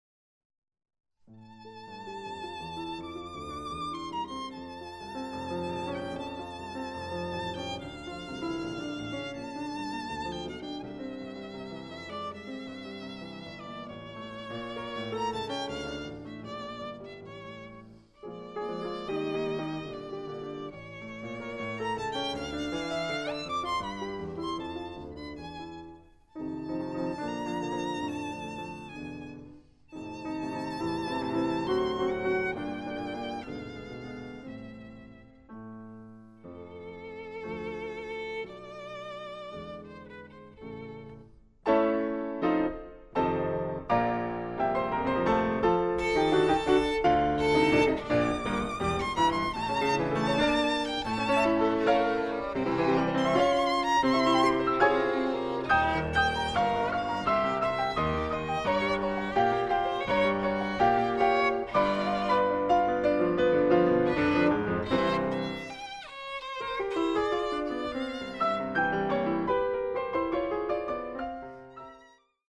Sonate per violino e pianoforte